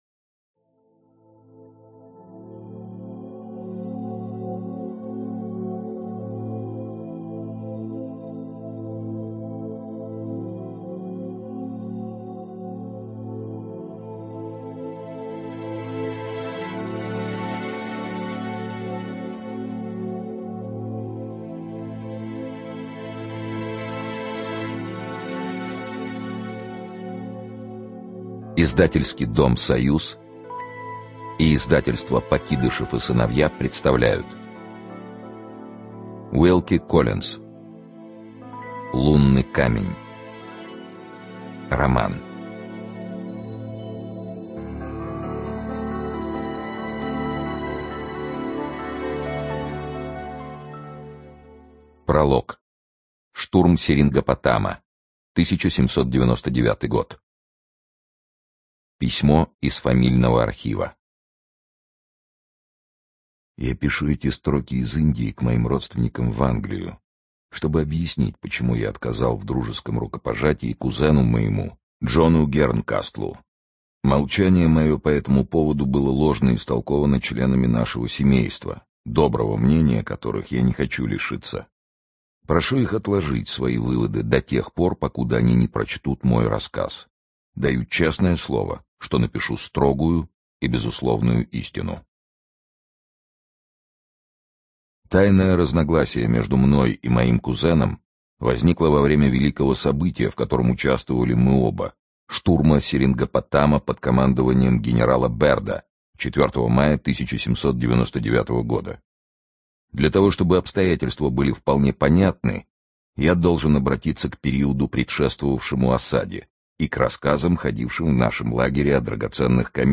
ЧитаетЧонишвили С.
Студия звукозаписиСоюз